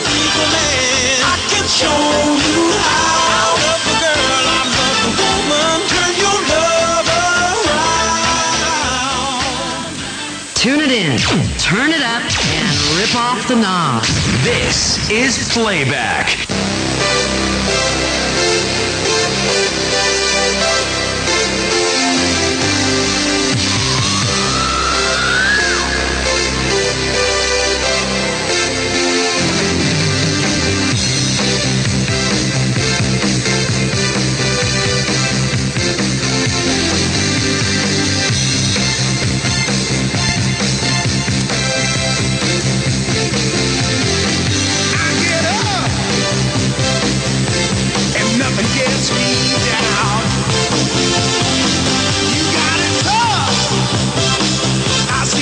6882 kHz - Playback International gave once again huge audio at night - 23.00- (O=3-5)